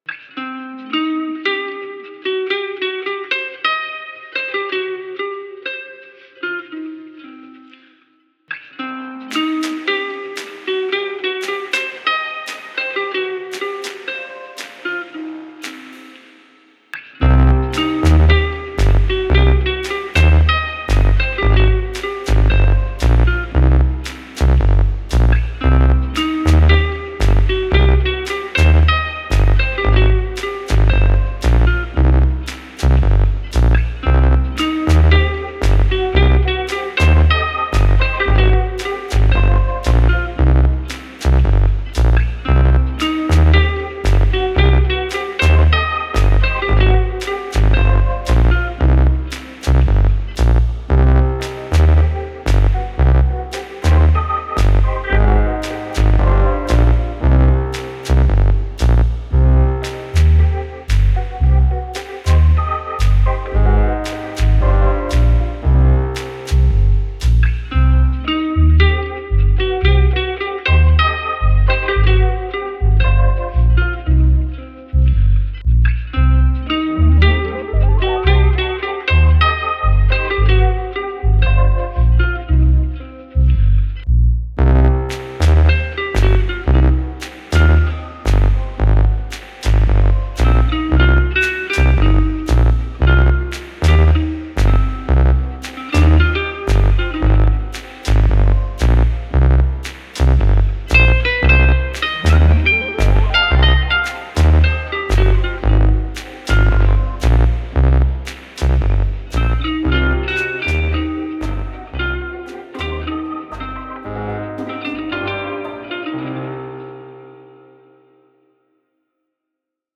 Somewhere between daydreams & midnight melancholy.